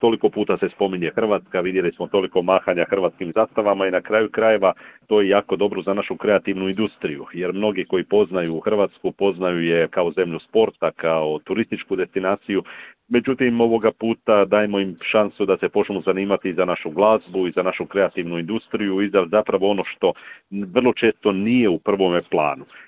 u razgovoru za Media servis.